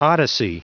Prononciation du mot odyssey en anglais (fichier audio)
Prononciation du mot : odyssey